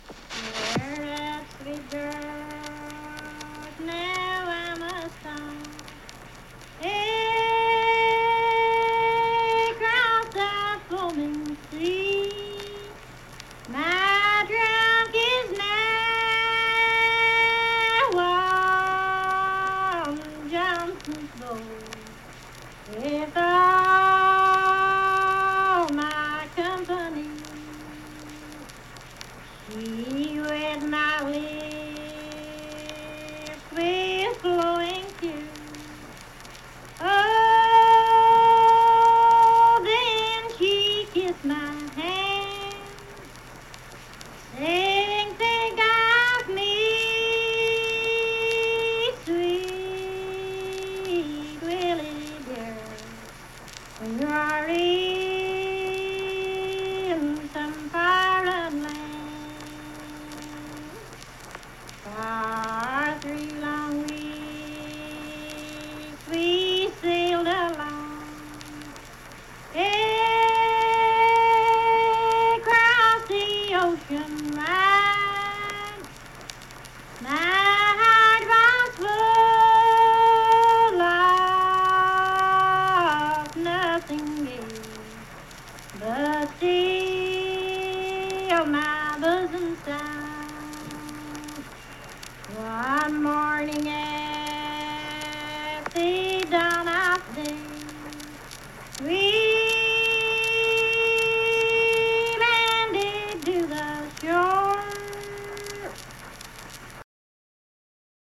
Unaccompanied vocal music
Voice (sung)
Lincoln County (W. Va.), Harts (W. Va.)